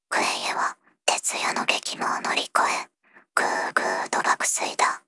voicevox-voice-corpus / ROHAN-corpus /ナースロボ＿タイプＴ_内緒話 /ROHAN4600_0046.wav